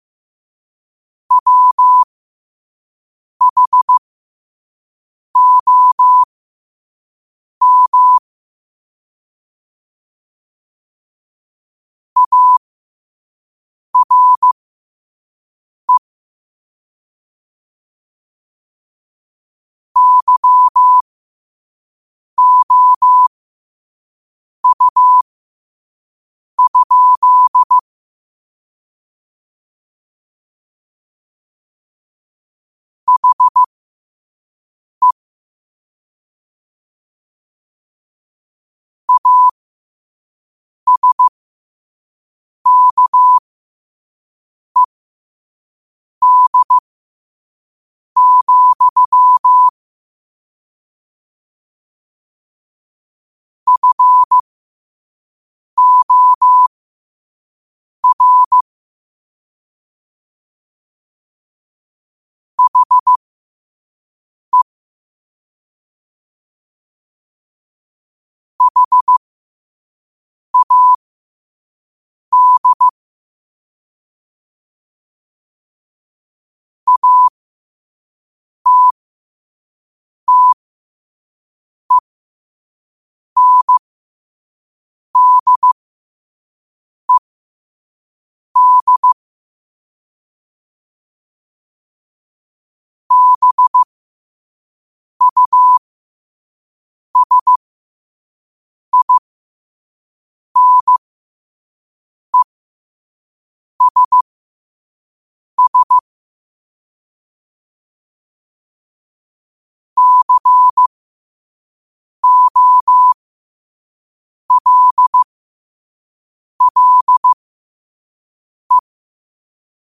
Quotes for Sat, 16 Aug 2025 in Morse Code at 5 words per minute.